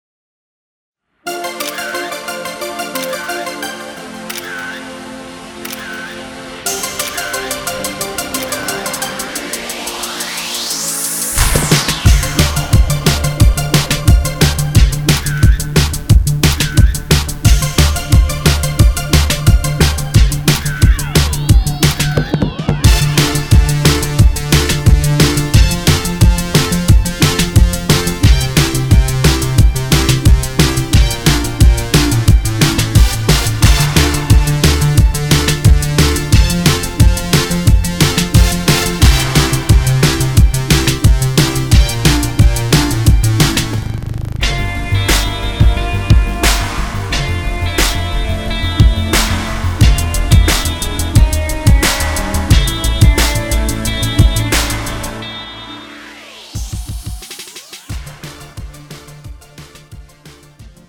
음정 (-1키)
장르 가요 구분 Premium MR